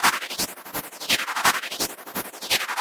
Index of /musicradar/rhythmic-inspiration-samples/85bpm
RI_RhythNoise_85-02.wav